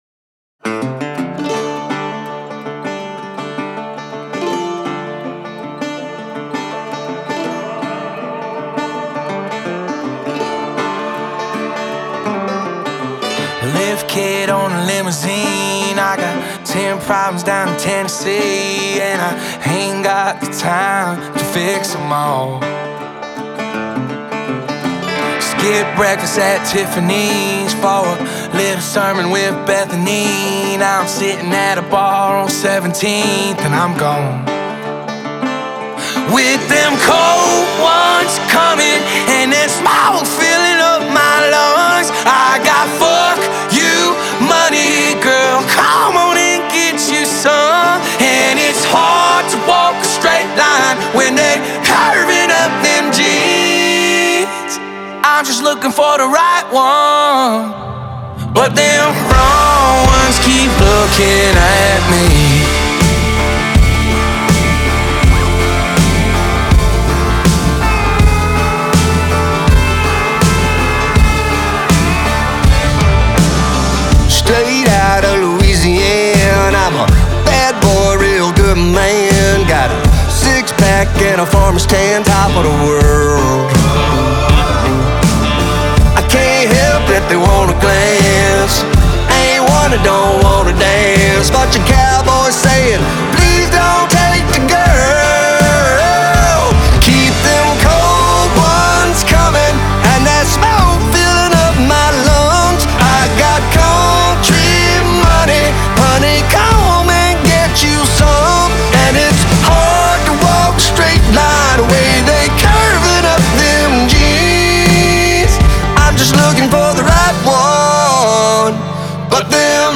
Country, Country Pop